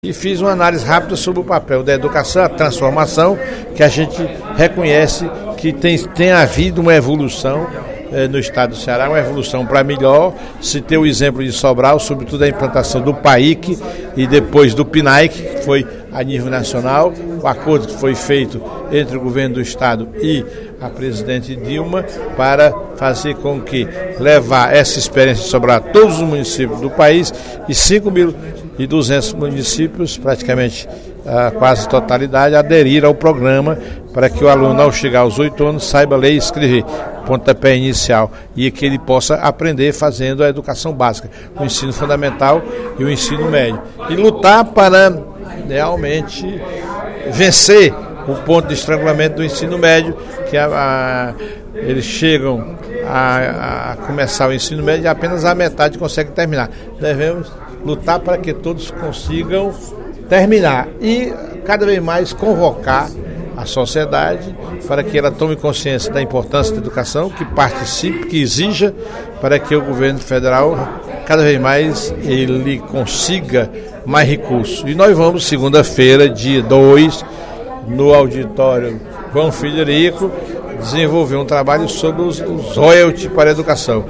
Durante o primeiro expediente da sessão plenária desta sexta-feira (22/11), o deputado Professor Teodoro (PSD) anunciou a realização de um seminário sobre os resultados do pré-sal para a educação.